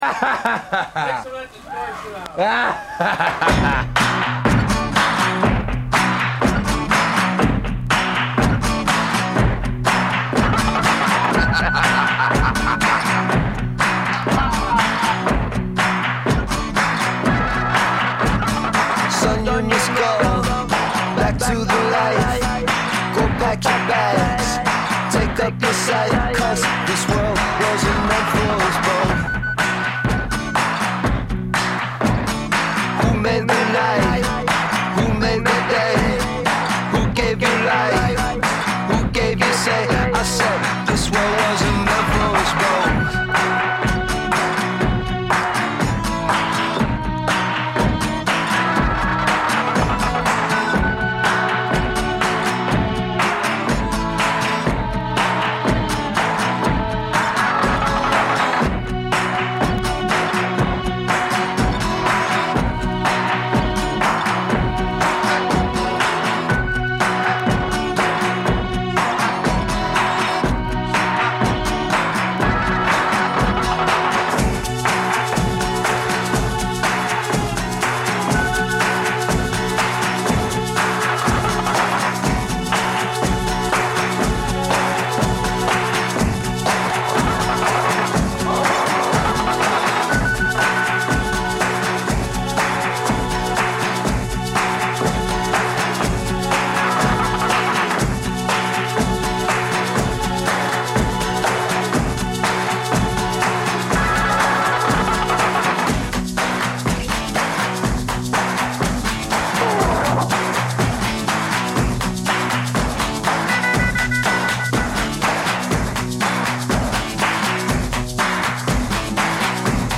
Zwei Musikfreunde duellieren sich mit raren Rock- und Punklegenden... - vinyl meets mp3... crossover zwischen den Welten, Urlaubsmusik und Undergroundperlen.
Einmal im Monat Freitag 21-22 Uhr Live on Air und in der Wiederholung montags drauf 12 Uhr.